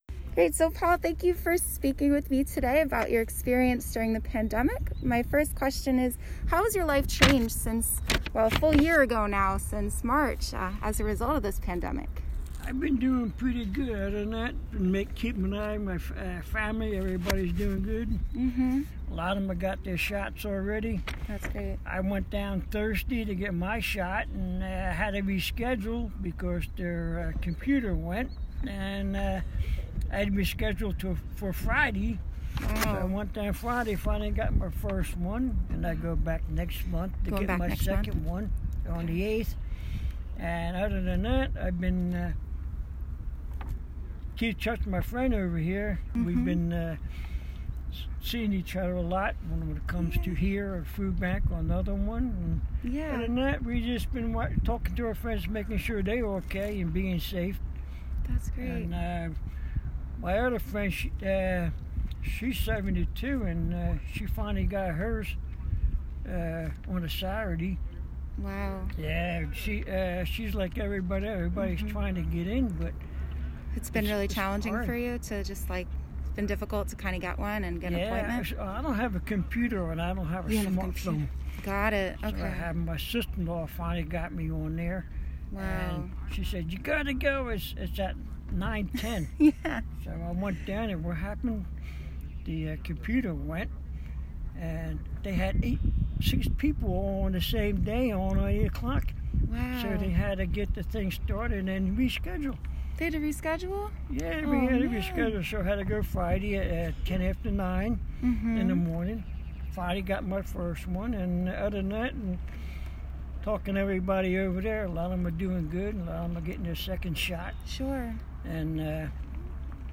Participant 493 Community Conversations Interview